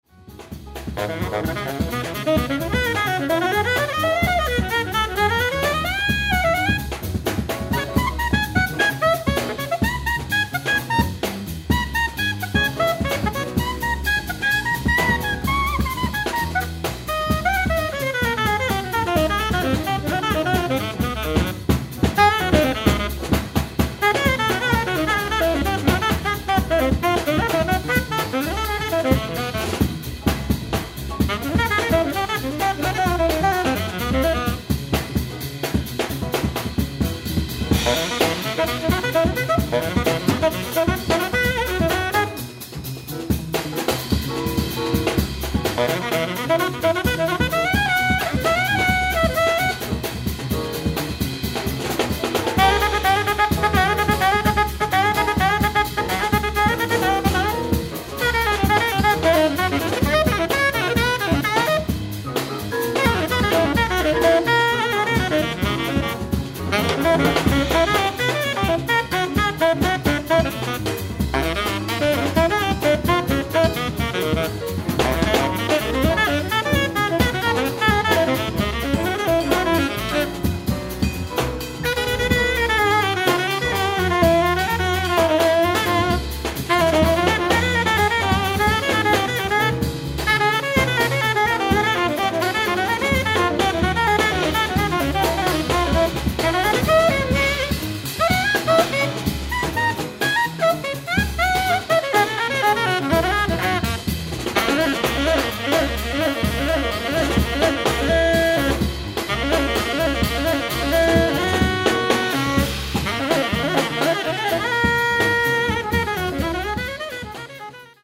ライブ・アット・ＳＦジャズ、サンフランシスコ、カリフォルニア 03/20/2022
※試聴用に実際より音質を落としています。